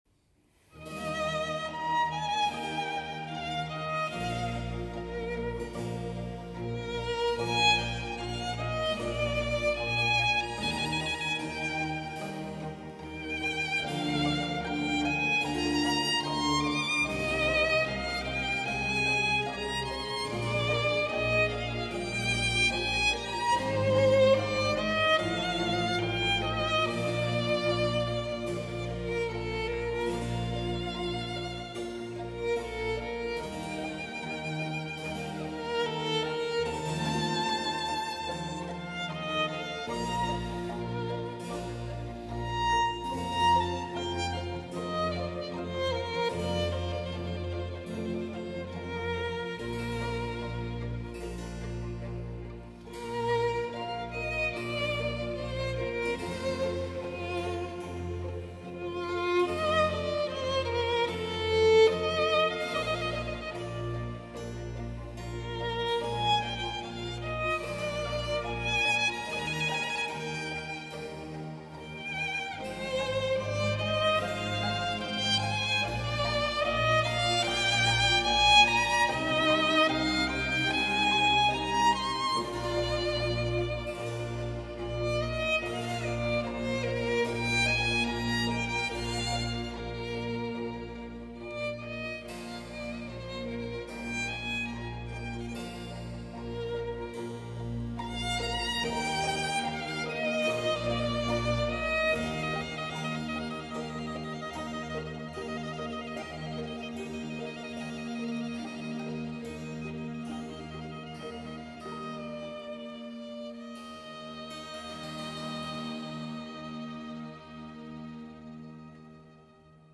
1.小提琴协奏曲《春》E大调 作品第8号之一
(1)快板